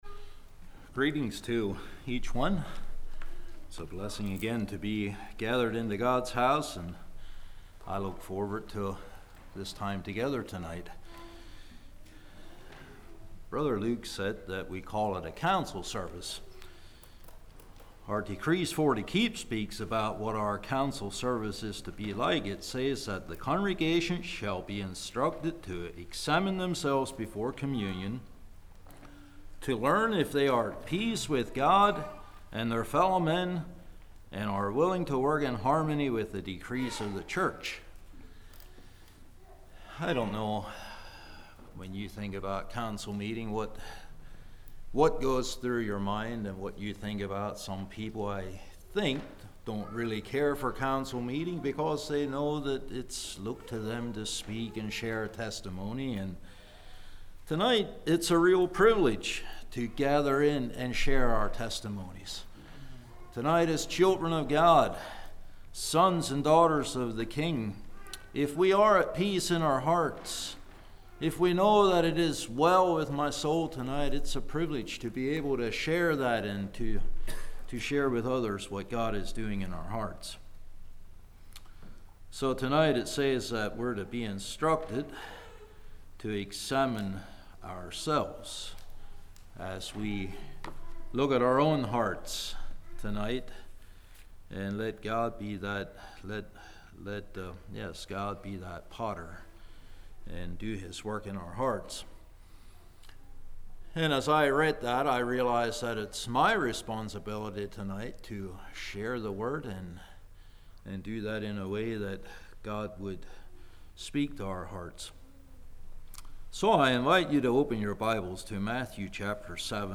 2019 Sermon ID